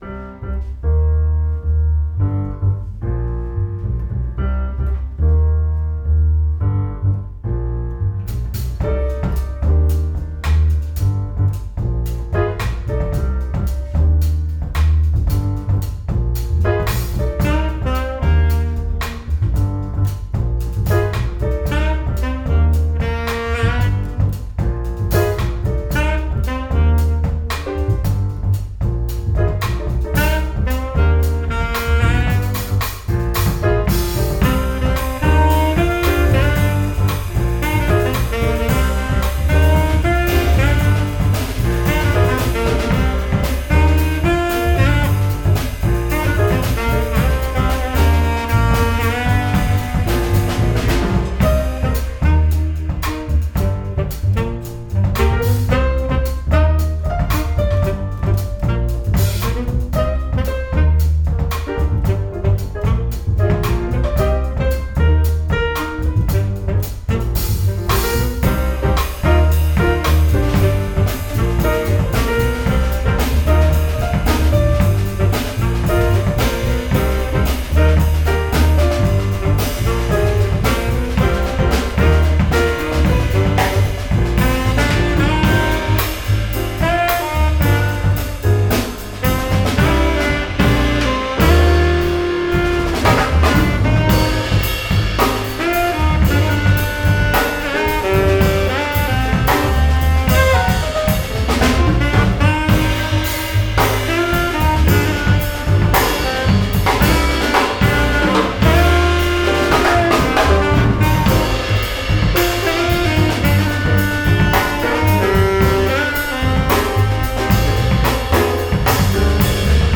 alto saxophone
piano
bass
drums
Jazz